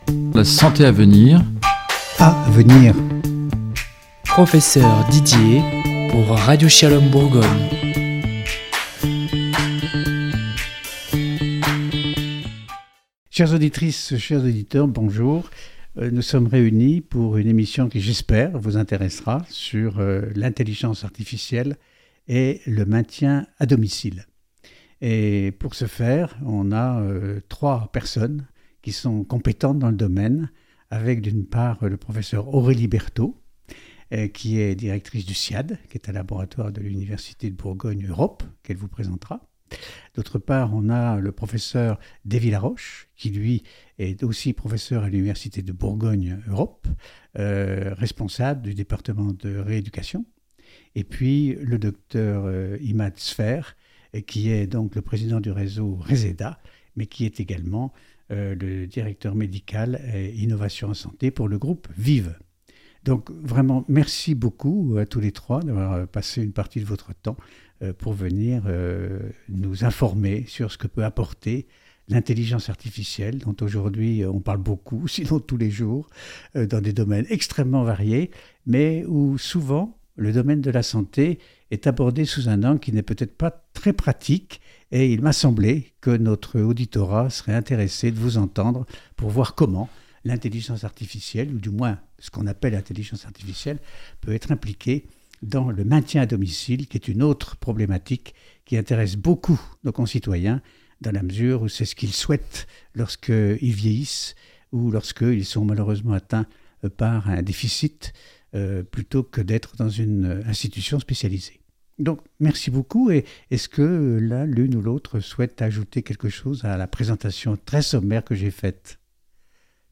L’Intelligence artificielle pourrait être une solution innovante s’intégrant à une démarche citoyenne adaptée. C’est pour mieux appréhender cette solution que Radio Shalom a décidé de consacrer un débat bénéficiant de trois spécialistes